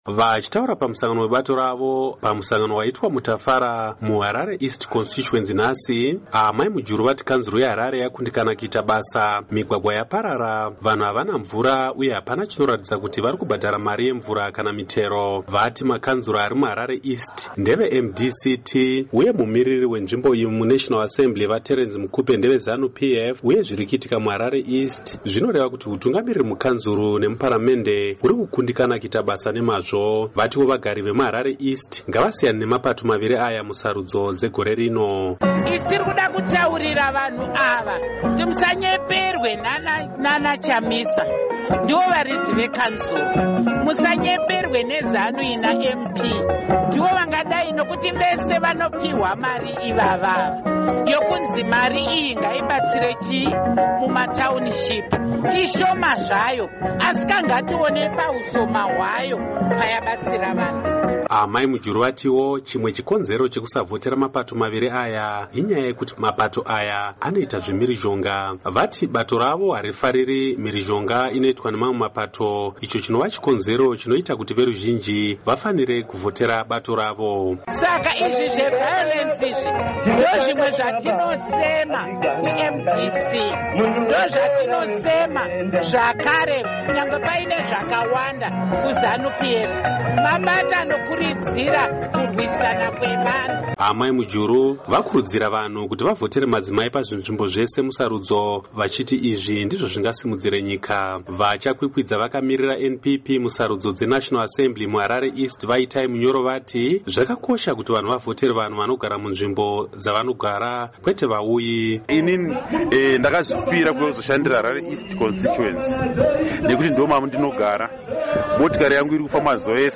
Vachitaura pamusangano webato ravo pamusangano waitwa muTafara muHarare East Constituency nhasi, Amai Mujuru vati kanzuru yeHarare yakundikana kuita basa, migwagwa yaparara, vanhu havana mvura uye hapana chinoratidza kuti vari kubhadhara mari yemvura kana yemitero.